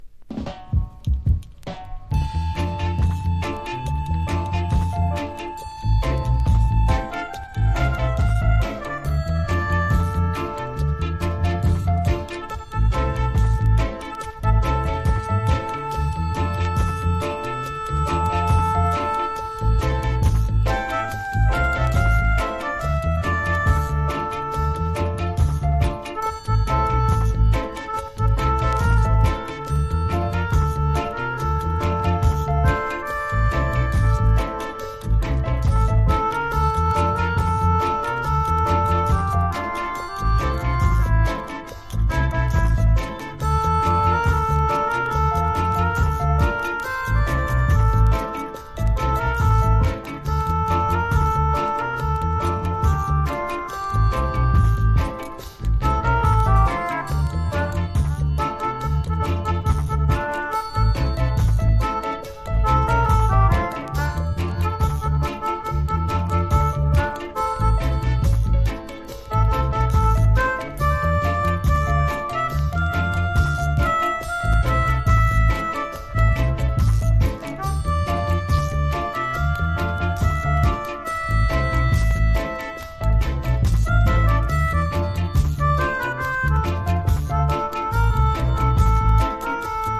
# ROOTS# DUB / UK DUB / NEW ROOTS# REGGAE